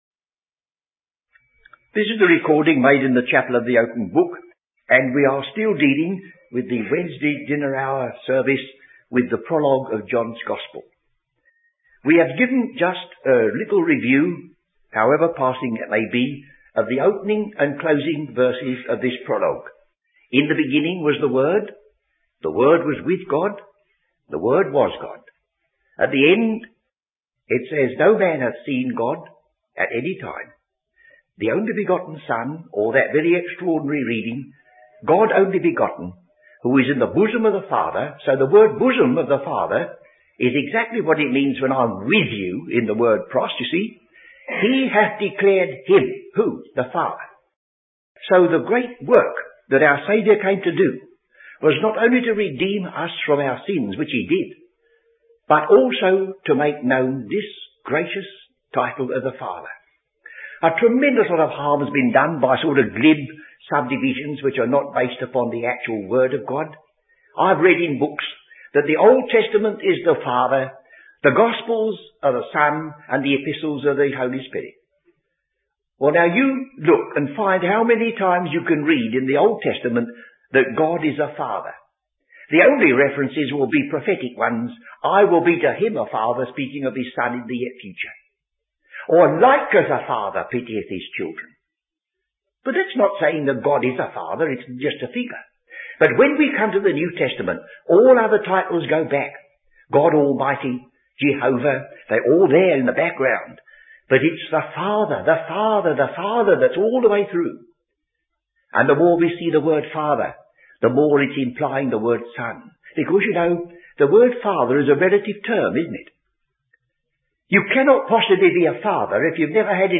Teaching